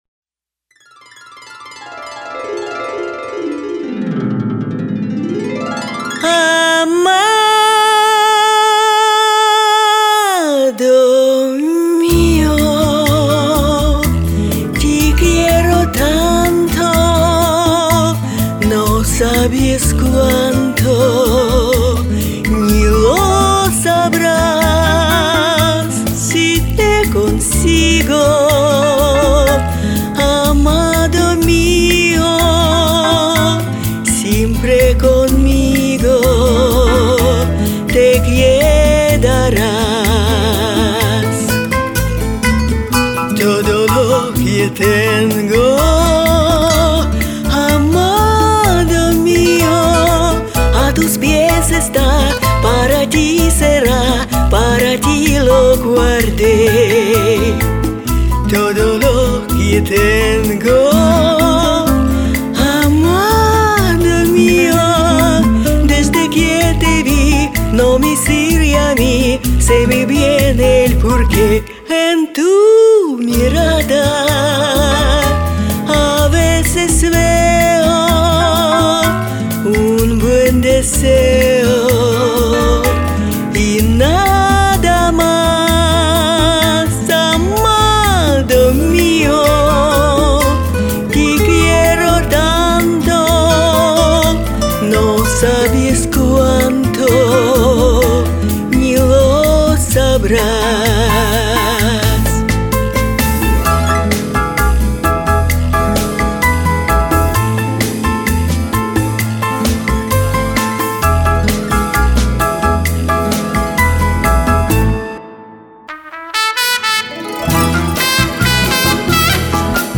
Комментарий инициатора: Приглашаю потанцевать в ритме танго.